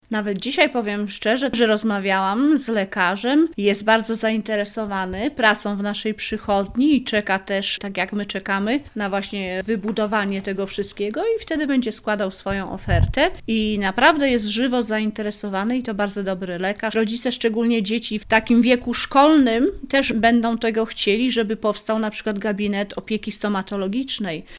– Najtańszą, zwycięską ofertę złożyło konsorcjum: Biuro Obsługi Budownictwa GOKA i Taurus, która opiewała na 2 mln zł: – mówi Dorota Bojar, sołtys Ochli: